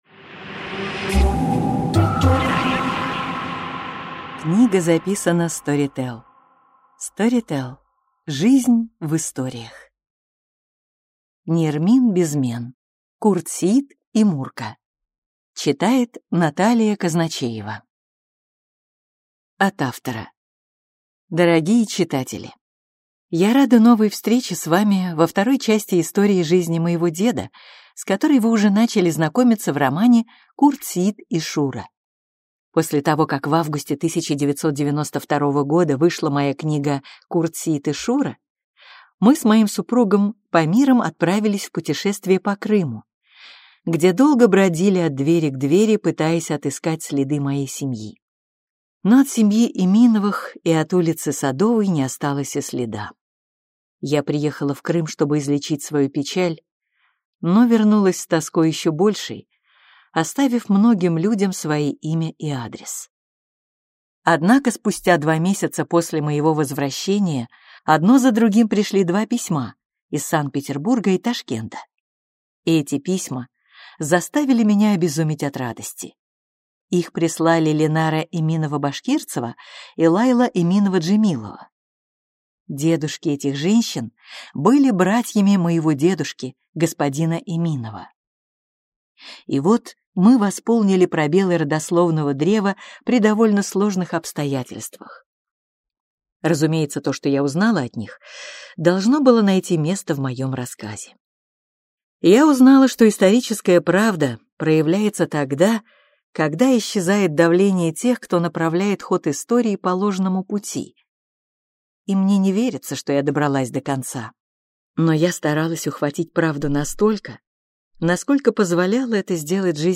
Аудиокнига Курт Сеит и Мурка | Библиотека аудиокниг